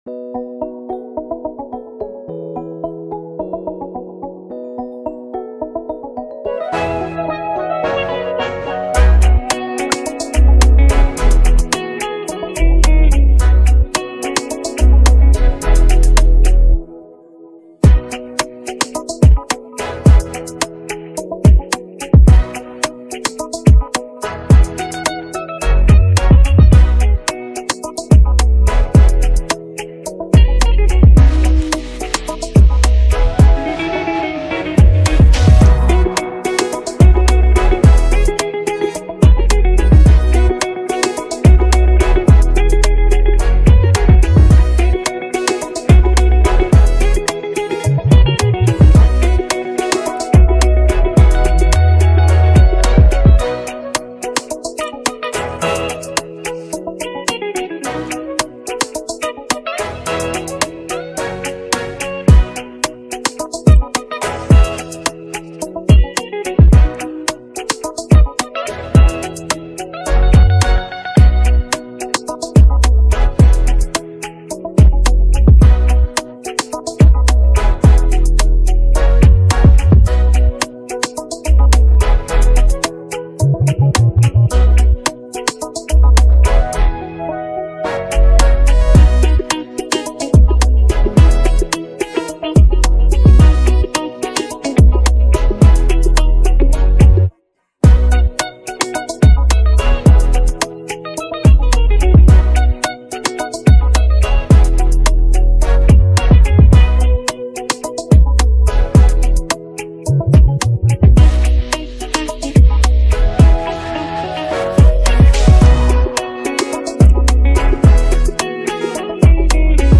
Afrobeats Highlife